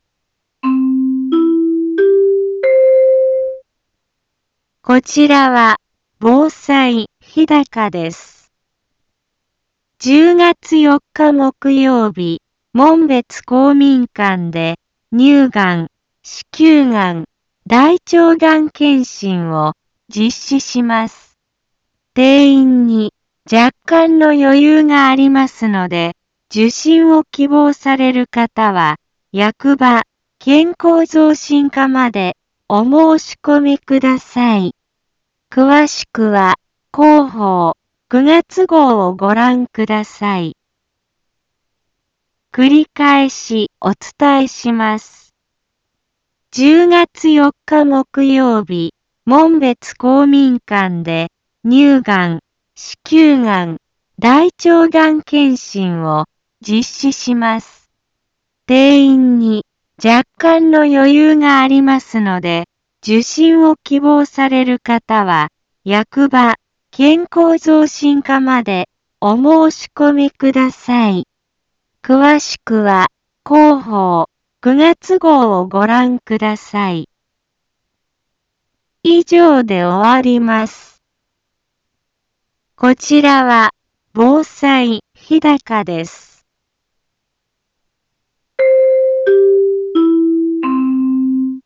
Back Home 一般放送情報 音声放送 再生 一般放送情報 登録日時：2018-09-25 15:03:26 タイトル：乳がん・子宮がん・大腸がん検診のお知らせ インフォメーション：10月4日木曜日、門別公民館で、乳がん、子宮がん、大腸がん検診を、実施します。